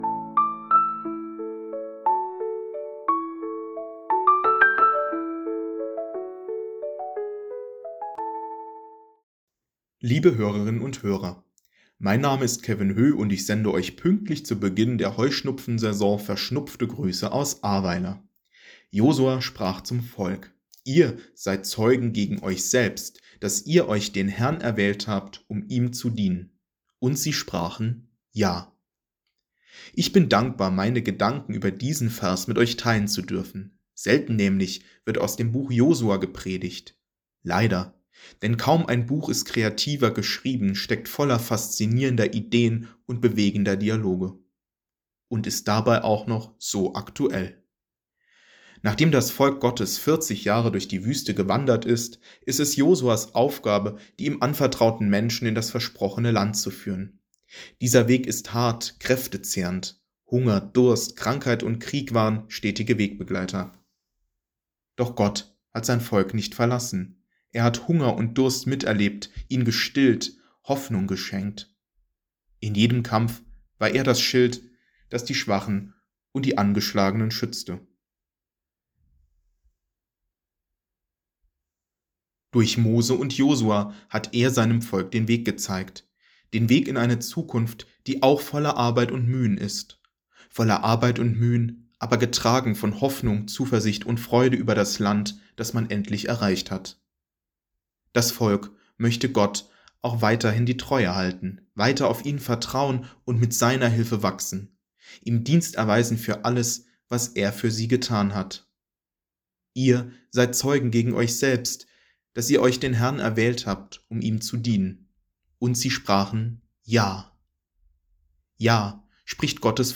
Losungsandacht für Dienstag, 11.03.2025